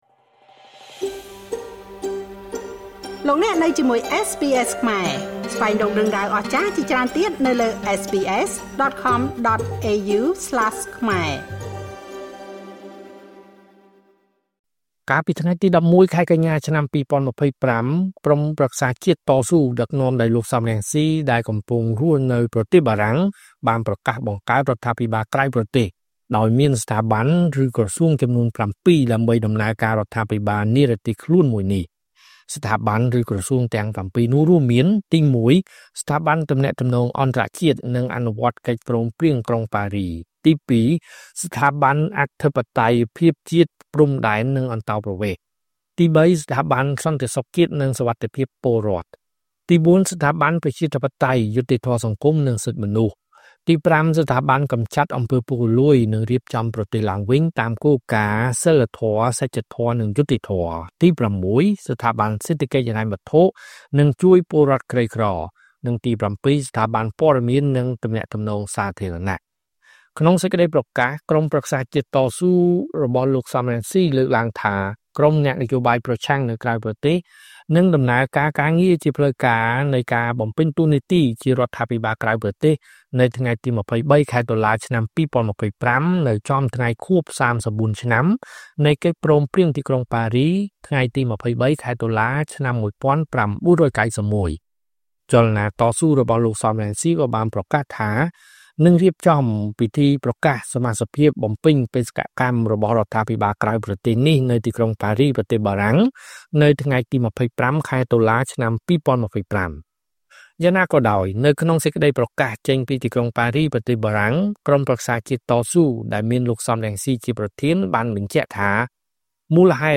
ការផ្សាយបន្តផ្ទាល់លើហ្វេសប៊ុគលោក Sam Rainsy កាលពីថ្ងៃ១១ កញ្ញា ២០២៥ ស្តីពីការប្រកាសបង្កើតរដ្ឋាភិបាលក្រៅប្រទេស